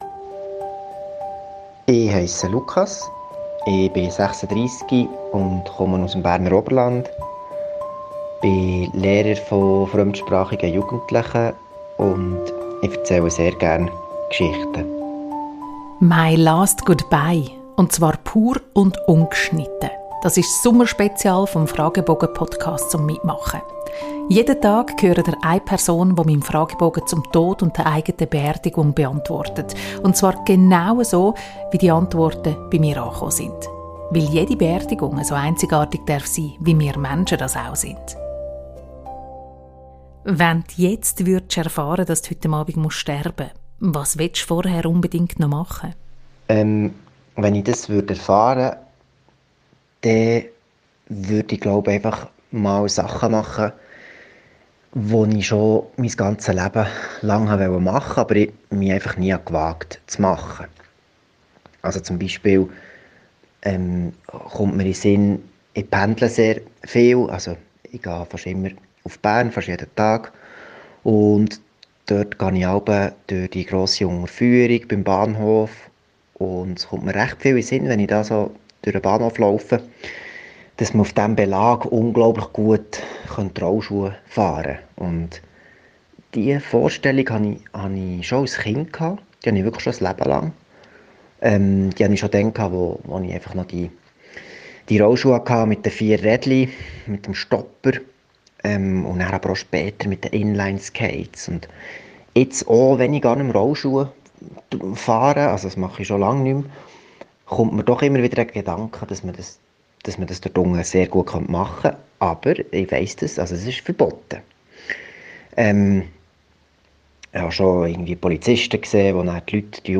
Pur und ungeschnitten.
Ihr hört die Antworten auf meinen MY LAST GOODBYE-Fragebogen genau so, wie sie via Whatsapp-Sprachmessage bei mir gelandet sind.